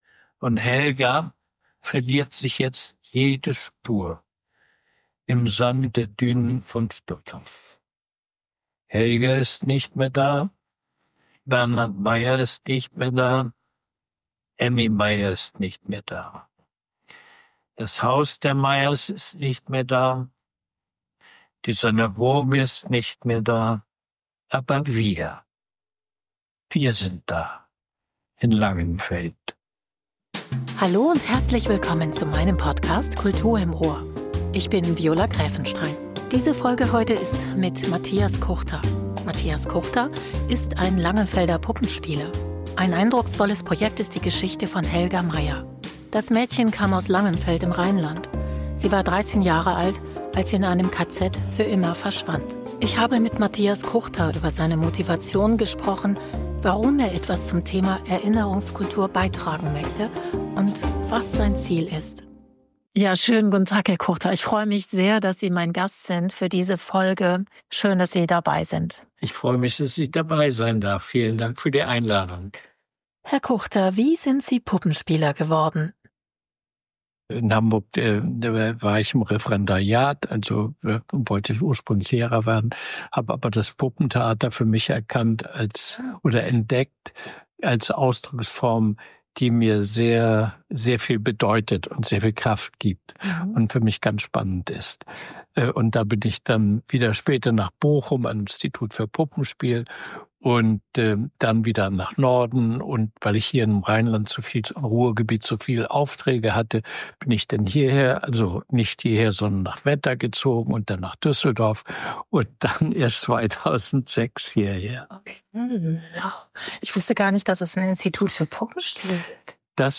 Das Interview